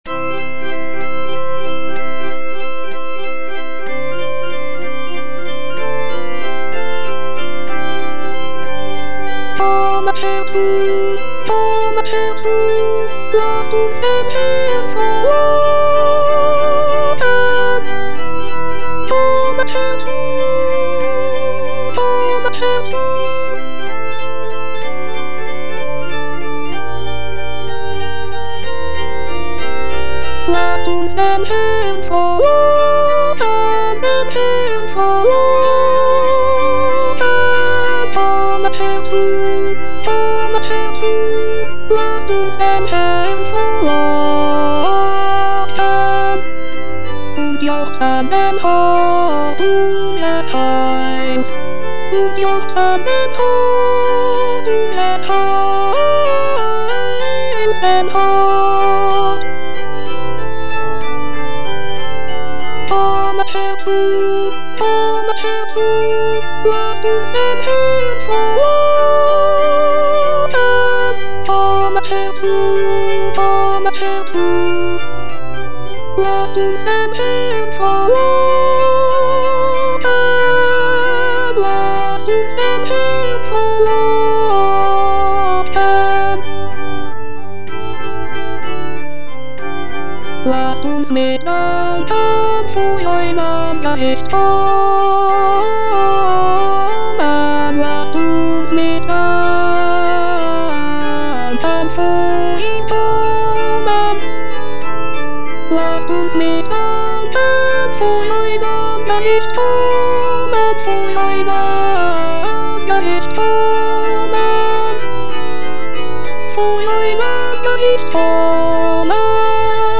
F. MENDELSSOHN - PSALM 95 op. 46 Kommet herzu - Alto (Voice with metronome) Ads stop: auto-stop